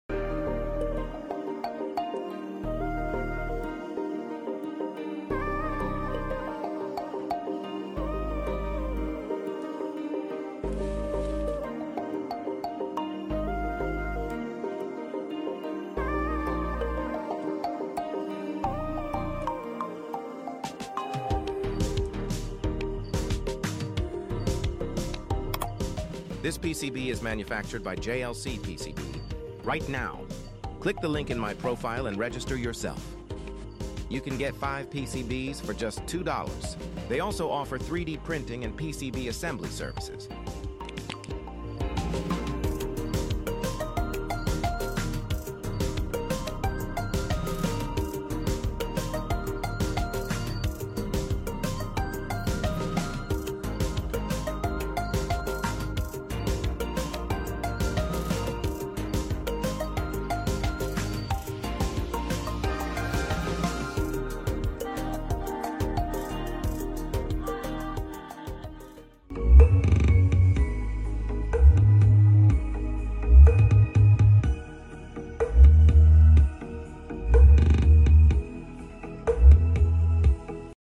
Wow, LED VU audio amplifier sound effects free download